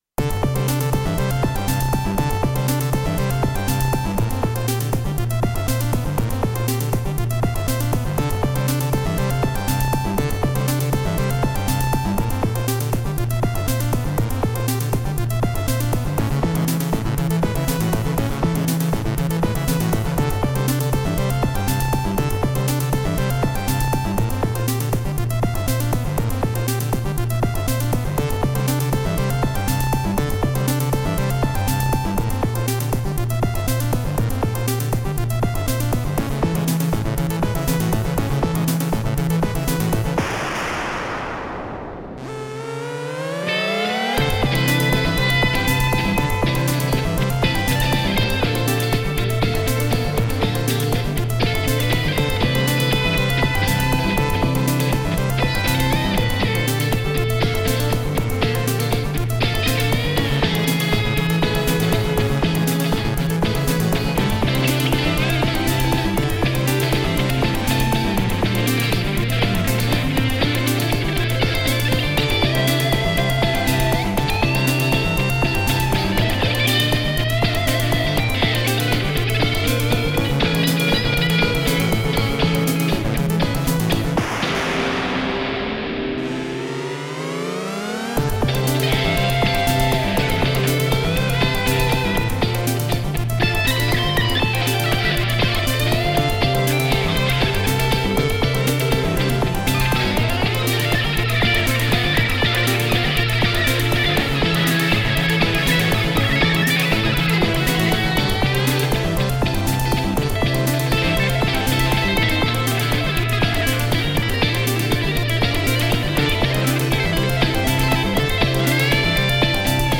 先日音ガ同で活躍したDS-10ですが、一応その前から自分で何か打ち込みで作ってみようかと入力していた曲があったので、ギターを重ねてミックスダウンしてみました。
私の好きなメジャーセブン系というかマイナーセブンフラットファイブというか、3コードのみで、カオスパッドを活用して旋律を作りました。最初の1ループはDS-10のみで、その後エレキギターを2本重ねてます。DS-10を使うとテクノっぽい感じになりがちで、テクノ系でギターを合わせたことはないので、どうもアドリブもさまになってませんが、まあ、サンプルということで。。